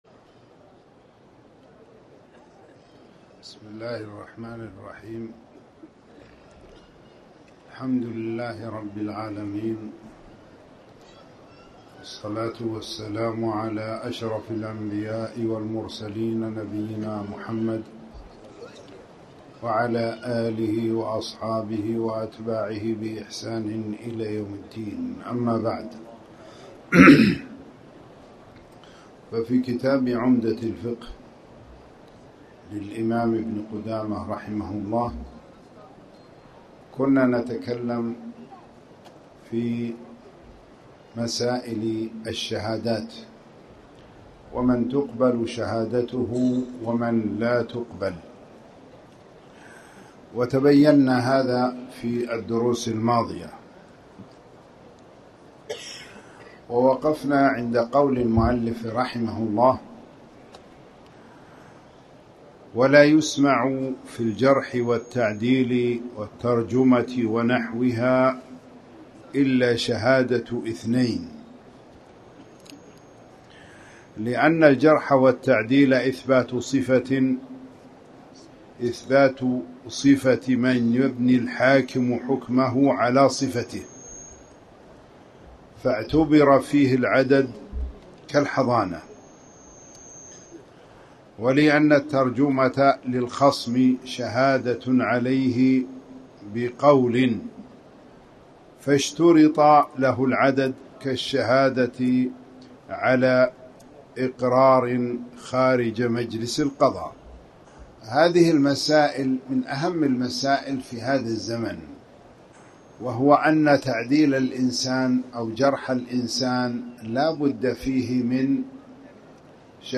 تاريخ النشر ٩ ربيع الثاني ١٤٣٩ هـ المكان: المسجد الحرام الشيخ